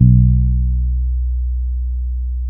-MM DUB  B 2.wav